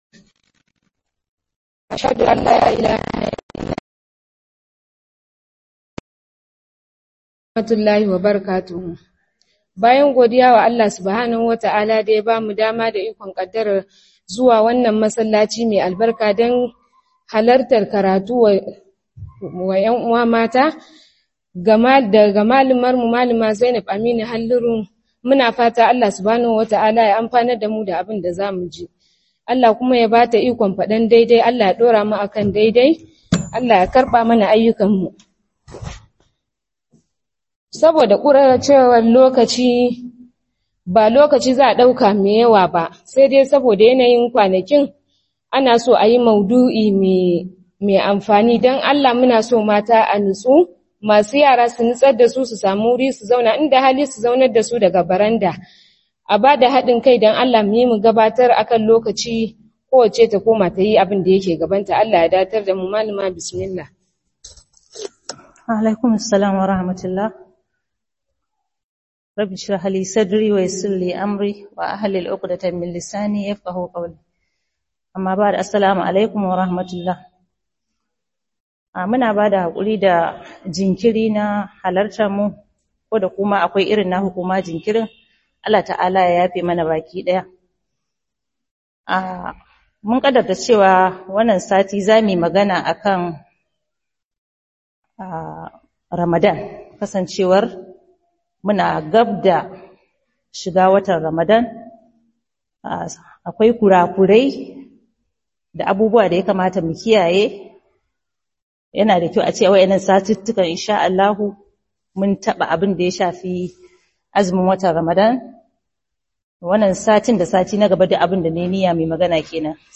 Maraba Da Ramadan - Muhadara